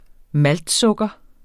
Udtale [ ˈmald- ]